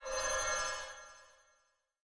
Uncanny Sound
神秘的声音